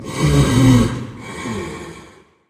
Minecraft Version Minecraft Version snapshot Latest Release | Latest Snapshot snapshot / assets / minecraft / sounds / mob / blaze / breathe2.ogg Compare With Compare With Latest Release | Latest Snapshot
breathe2.ogg